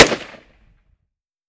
5.56 M4 Rifle - Gunshot A 004.wav